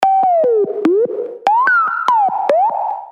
• Качество: 320, Stereo
без слов
космические